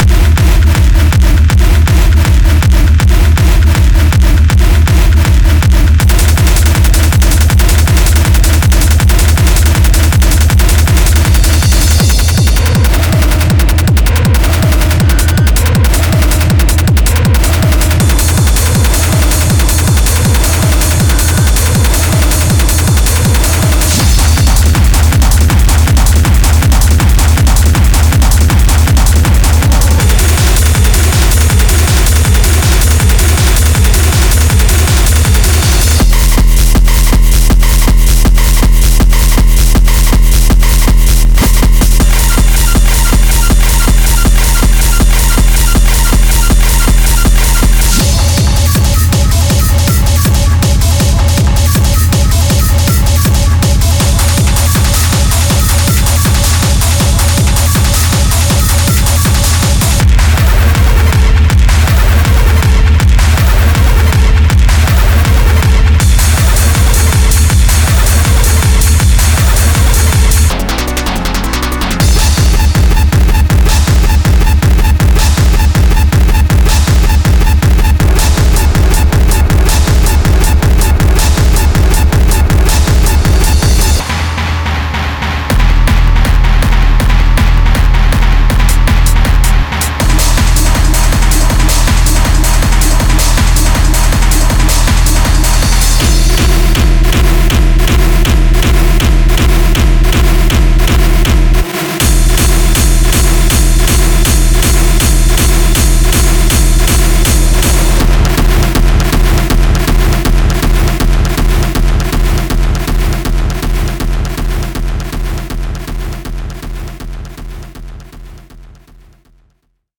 今回のエディションは、よりハードでダーティなサウンドを求める現代のプロデューサーに向けて制作されました。
このパックには、鋭いシンセとハードなドラムが満載。
部屋全体を揺るがすような爆発的キックドラムの準備をお忘れなく。
ループは160 BPMで統一されており、さまざまなスタイルに柔軟に対応できます。
Genre:Industrial Techno